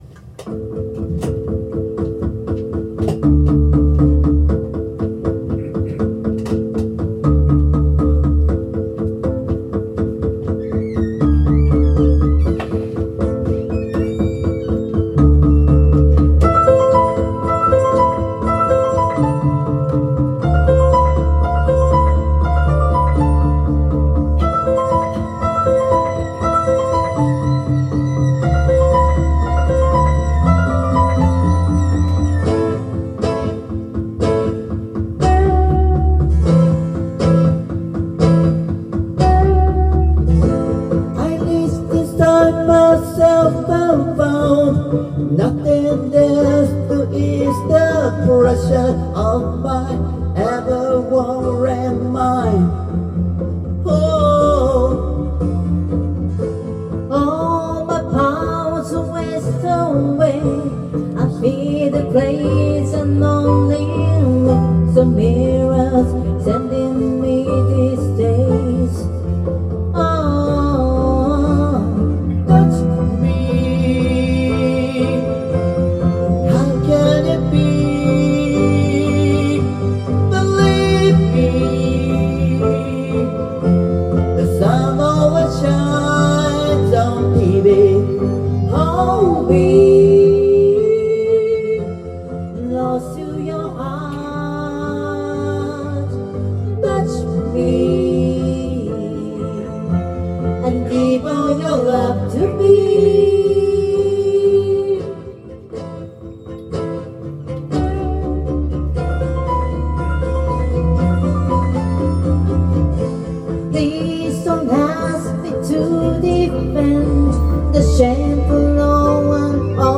Duet & Chorus Night Vol. 22 TURN TABLE